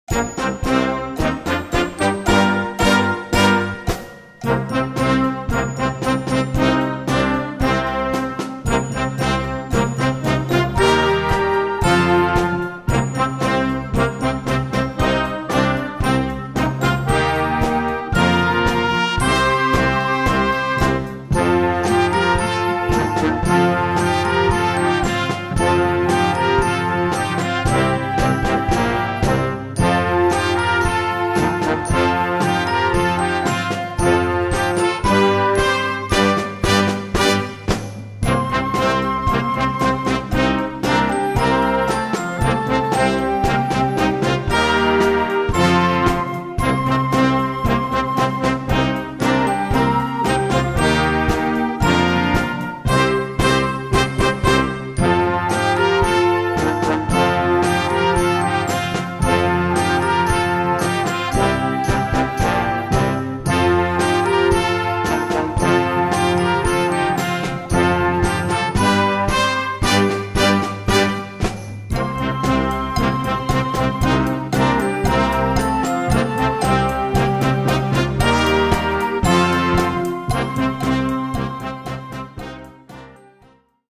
Répertoire pour Harmonie/fanfare - Concert Band